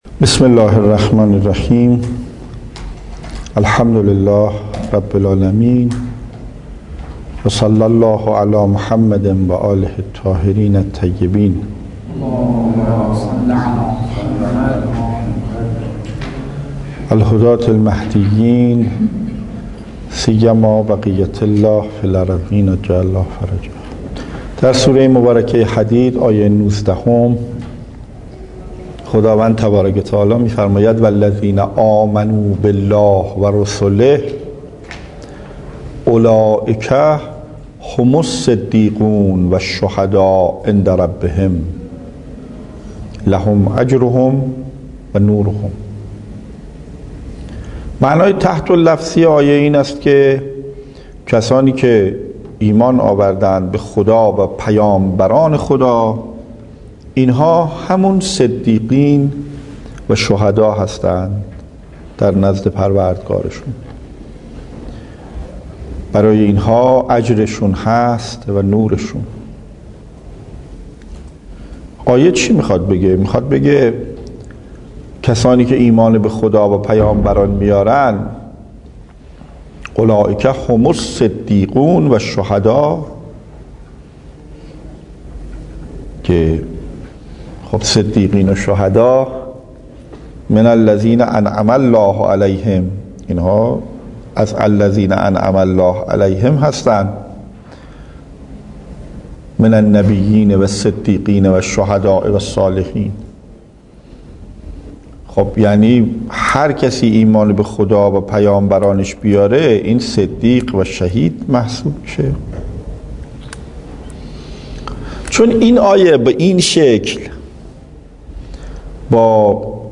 تفسیر قرآن